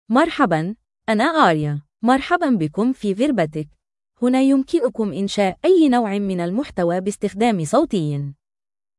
Aria — Female Arabic (Standard) AI Voice | TTS, Voice Cloning & Video | Verbatik AI
Aria is a female AI voice for Arabic (Standard).
Voice sample
Listen to Aria's female Arabic voice.
Female